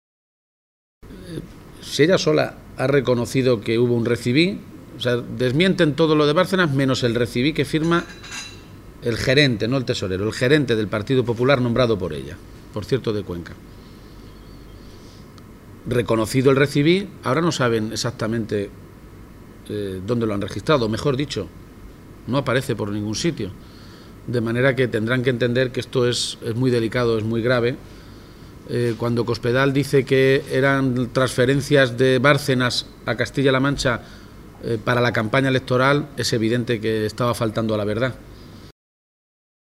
García-Page, que compartió un desayuno informativo con los medios de comunicación en Cuenca, insistió una vez más en la necesidad de que la presidenta de Castilla-La Mancha, María Dolores de Cospedal, comparezca en las Cortes regionales tras las últimas informaciones desprendidas del denominado caso Bárcenas.